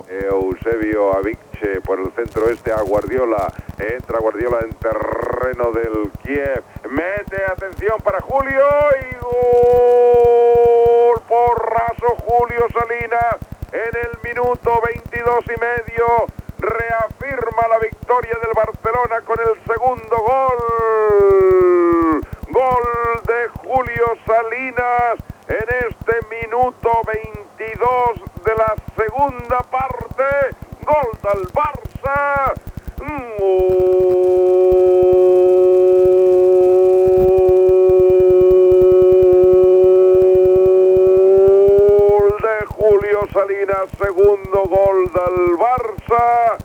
Transmissiò del partit Dynamo Kyiv - Barça, gol del 0 -2.
Esportiu